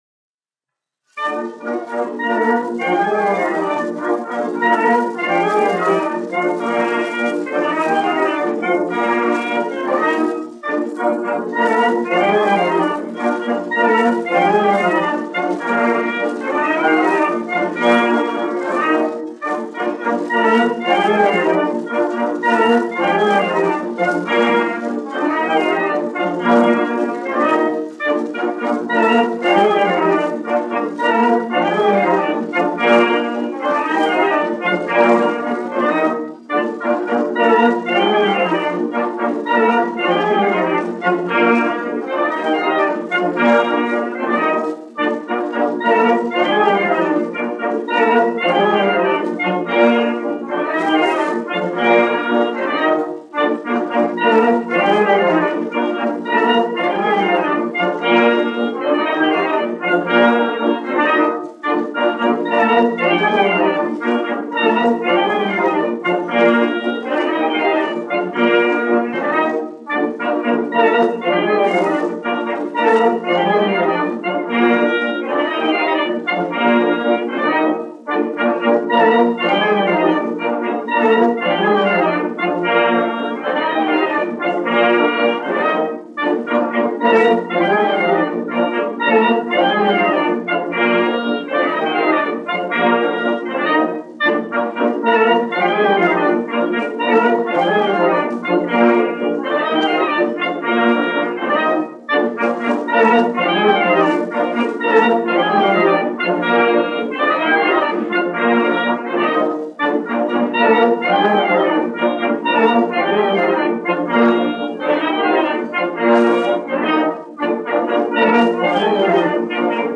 Latvijas 4. Valmieras kājnieku pulka orķestris, izpildītājs
1 skpl. : analogs, 78 apgr/min, mono ; 25 cm
Tautas deju mūzika
Pūtēju orķestra mūzika
Skaņuplate